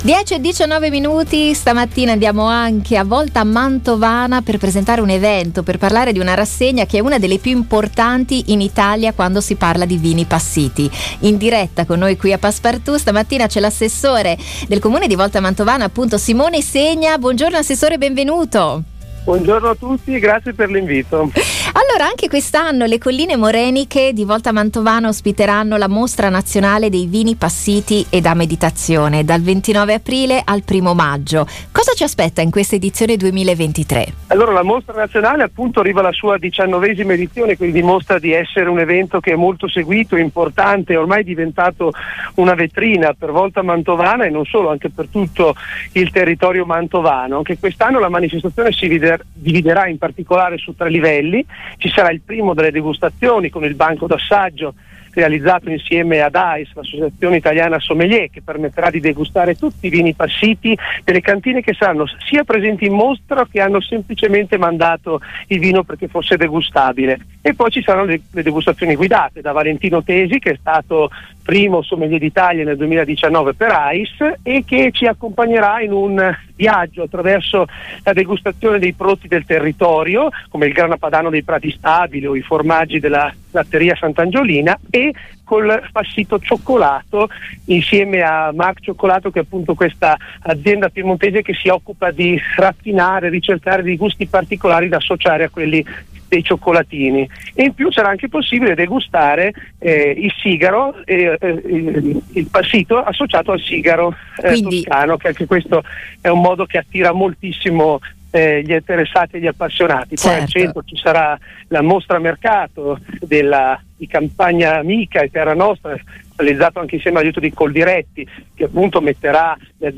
L’Assessore del Comune di Volta Mantovana, Simone Segna, ne ha parlato al microfono